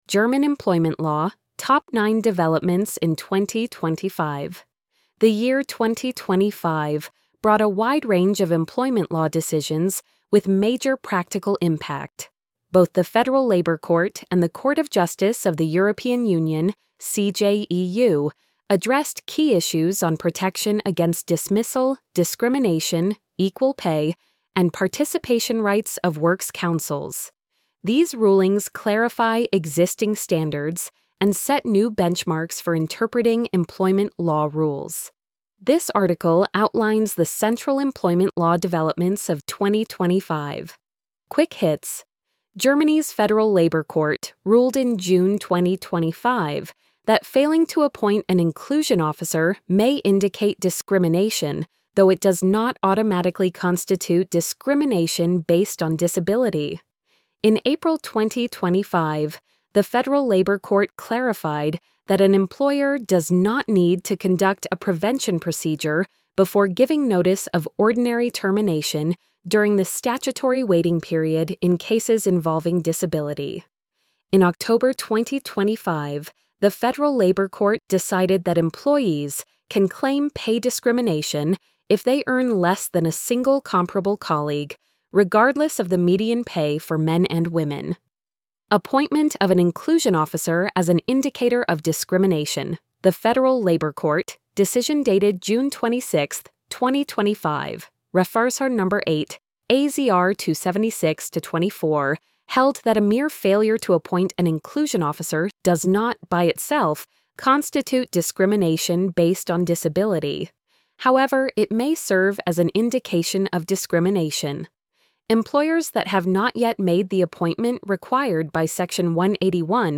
german-employment-law-top-9-developments-in-2025-tts.mp3